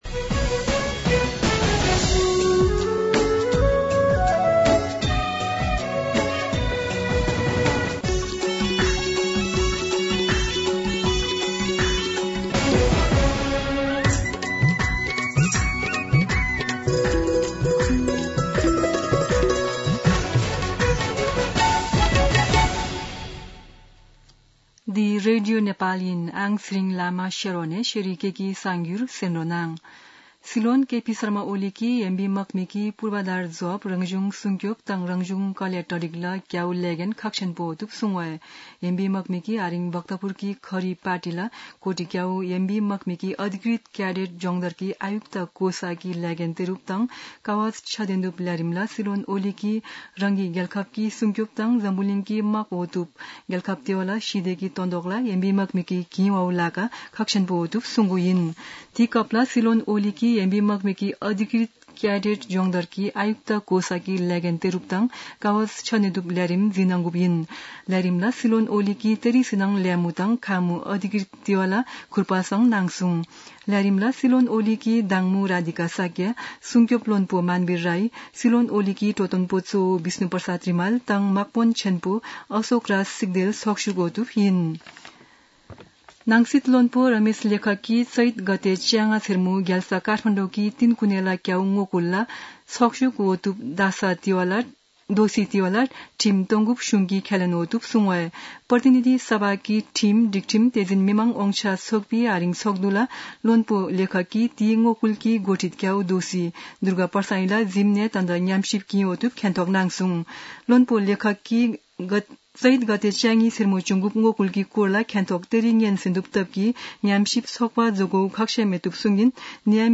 शेर्पा भाषाको समाचार : २९ चैत , २०८१
Sherpa-news.mp3